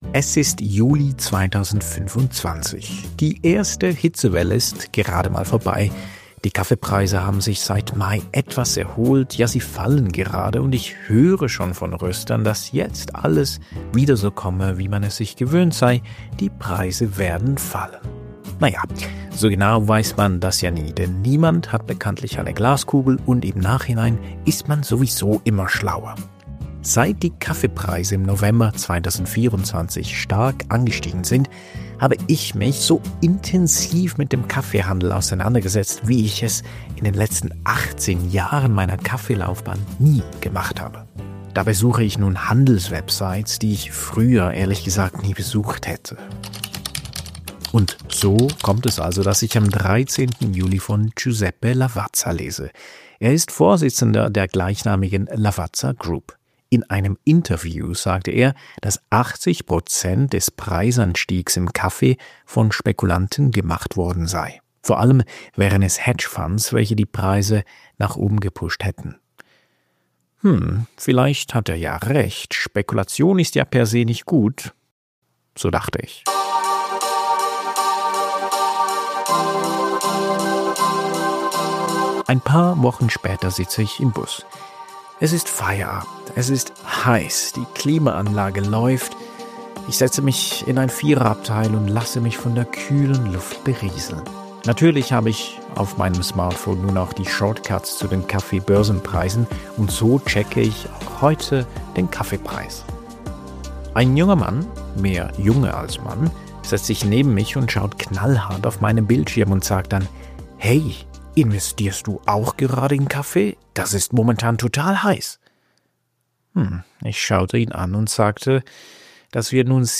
Zwischen Börsen-Charts, Terminmärkten und realen Kaffeebauern entsteht ein Spannungsfeld, in dem Erwartungen, Risiken und Marktmacht über Existenzen entscheiden. Ich spreche mit Expert:innen entlang der gesamten Wertschöpfungskette und beleuchte, warum Preisbildung im Kaffee so viel mehr ist als Angebot und Nachfrage.